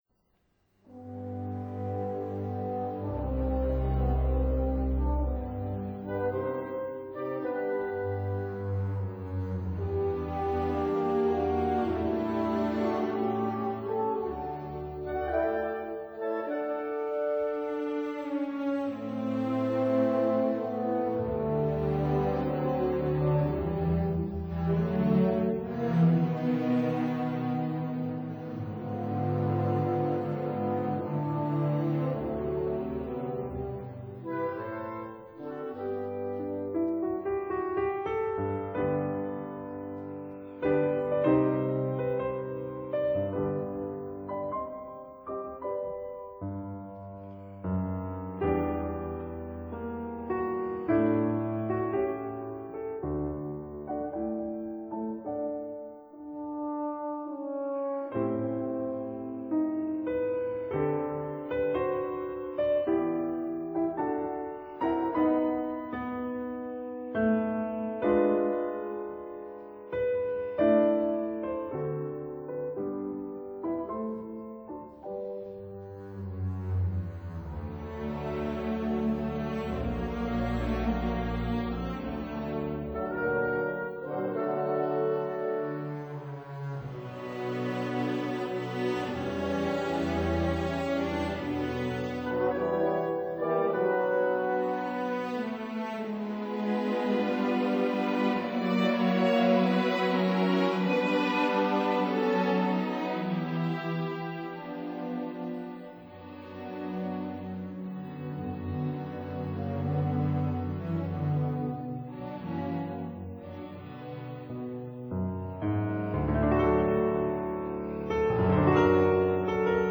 •(01) Piano Concerto in B minor
•(05) Serenade for String Orchestra in G minor, Op. 35
piano